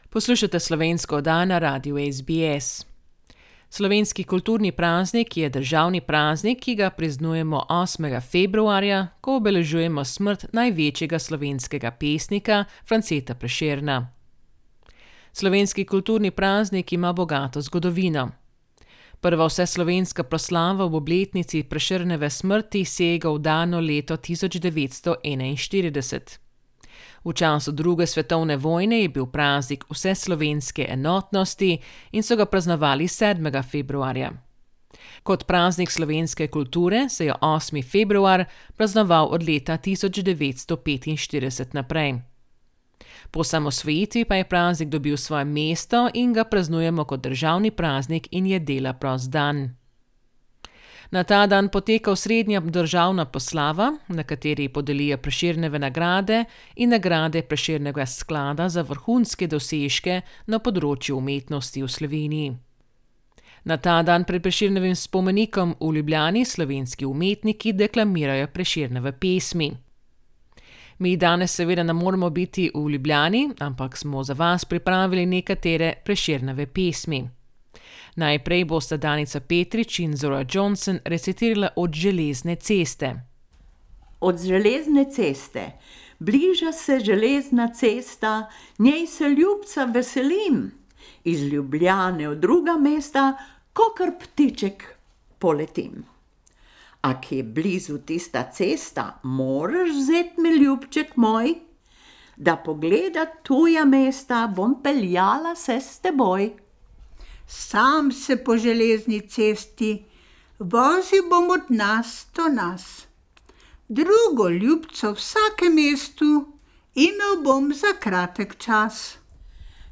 sta recitirali Prešernovo pesem Od železne ceste.